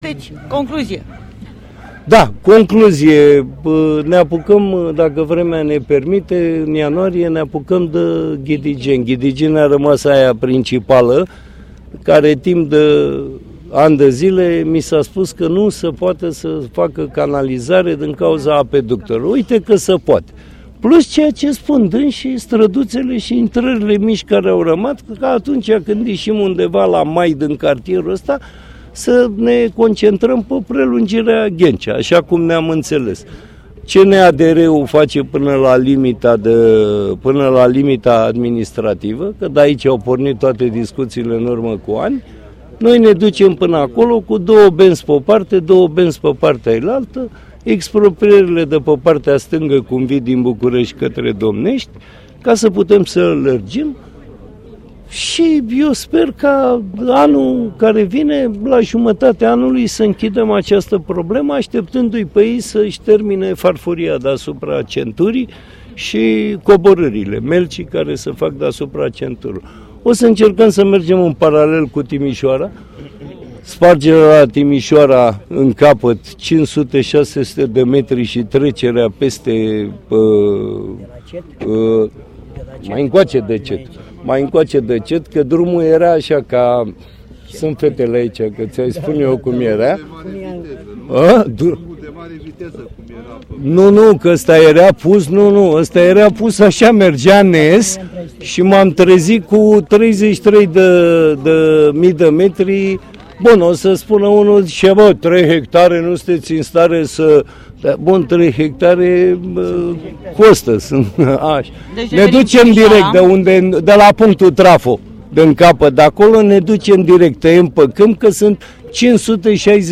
Primarul general Sorin Oprescu: